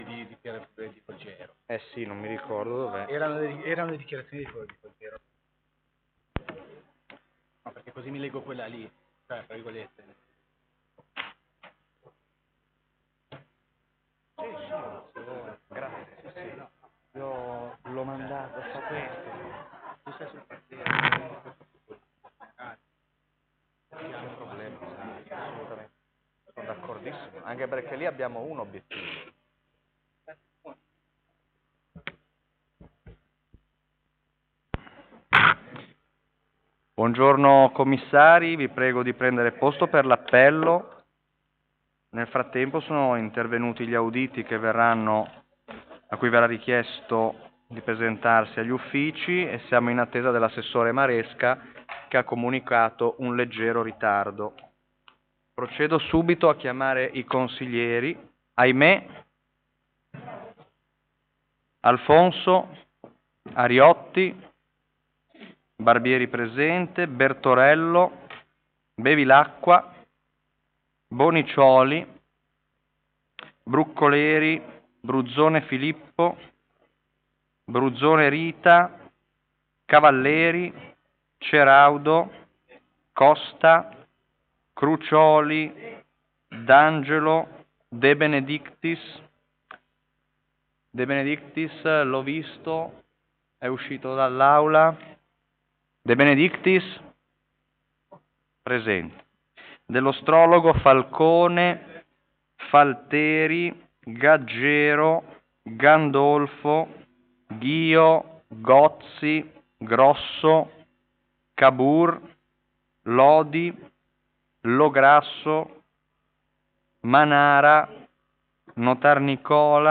Commissione consiliare o Consiglio Comunale: 6 - Sviluppo Economico
Presso la Sala Consiliare di Palazzo Tursi - Albini